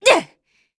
Glenwys-Vox_Attack3_kr.wav